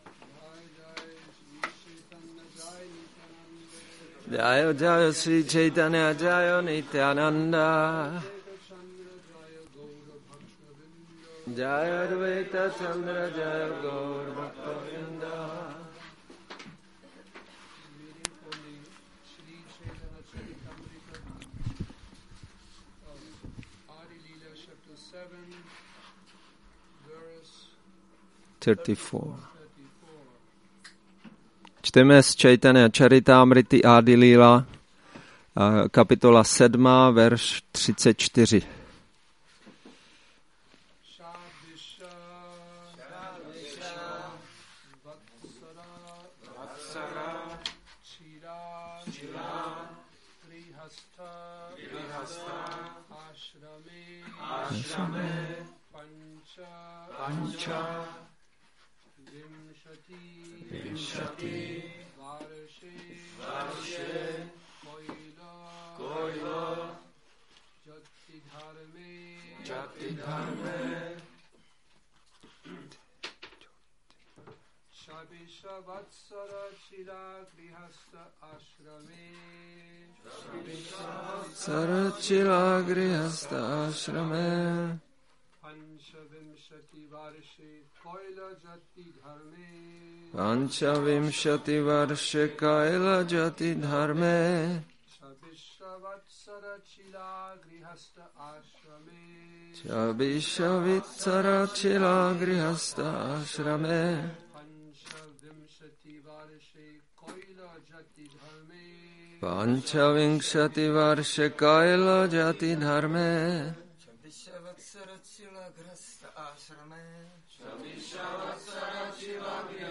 Šrí Šrí Nitái Navadvípačandra mandir